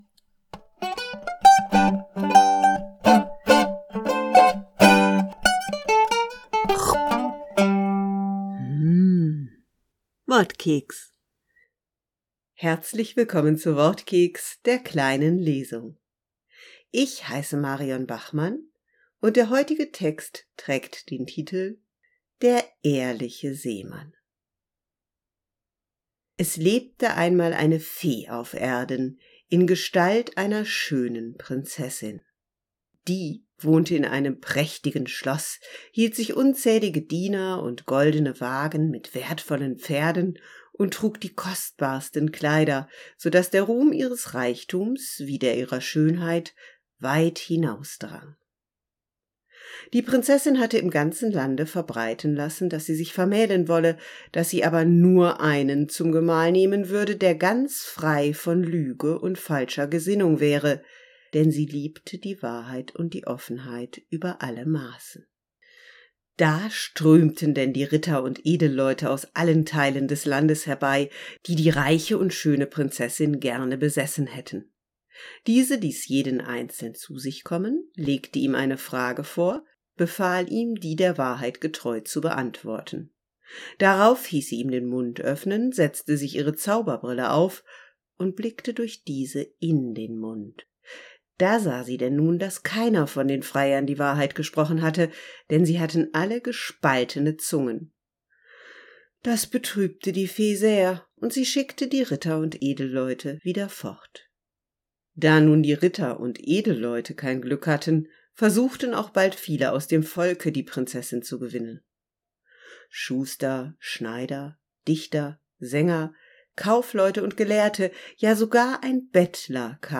Heute in der kleinen Lesung: Reichtum, Schönheit, Zauberei und